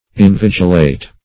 invigilate - definition of invigilate - synonyms, pronunciation, spelling from Free Dictionary
invigilate.mp3